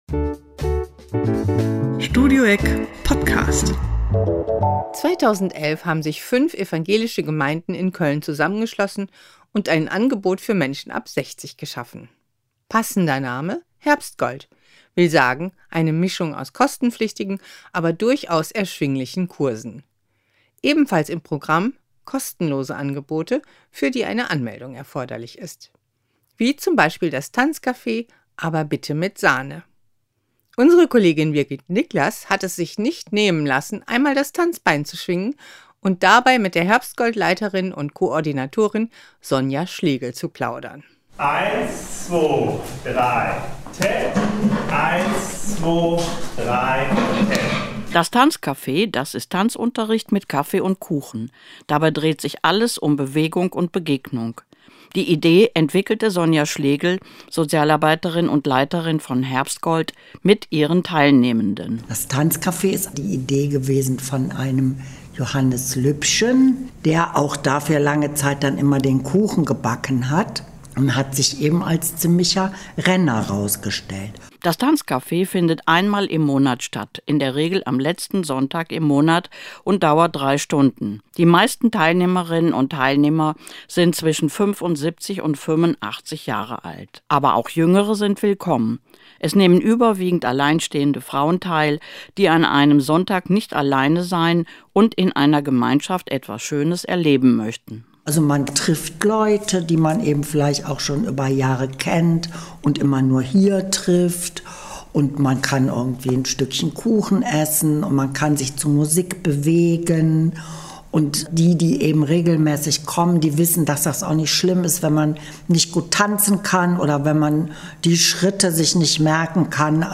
gesprochen und am Tanzcafé teilgenommen